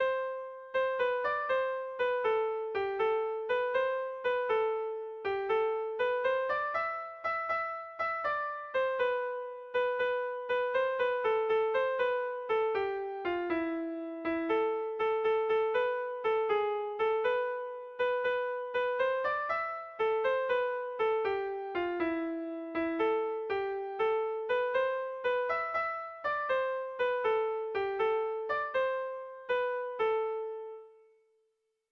Kontakizunezkoa
Hamabiko txikia (hg) / Sei puntuko txikia (ip)
ABDEDF